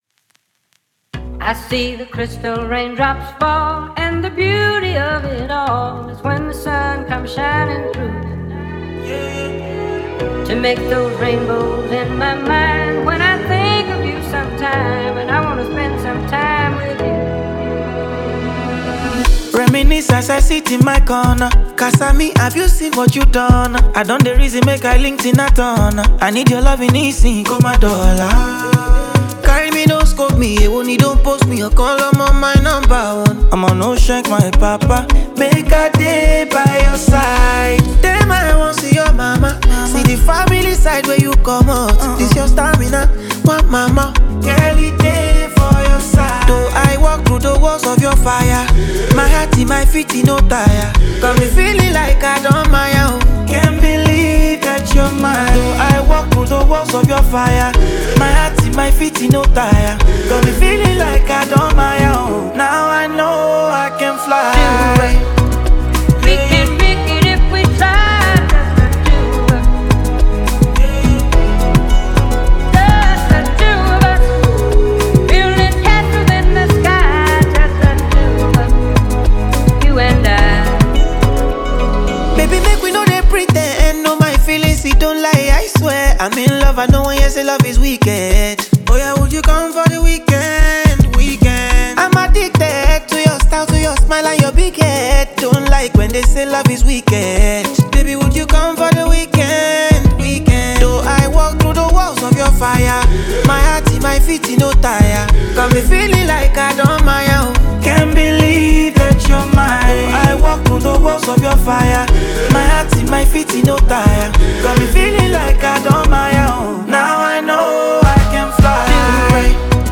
blend of afrobeat, highlife, and contemporary sounds